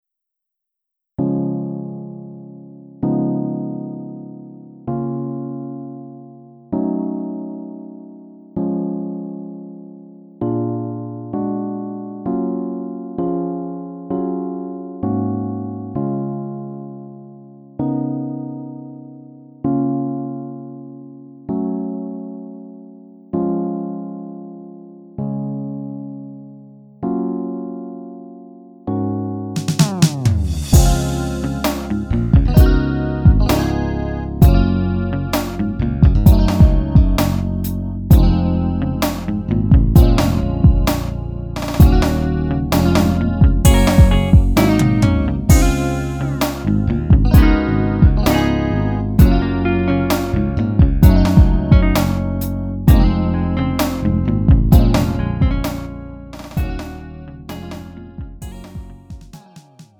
음정 -1키 3:02
장르 가요 구분 Lite MR
Lite MR은 저렴한 가격에 간단한 연습이나 취미용으로 활용할 수 있는 가벼운 반주입니다.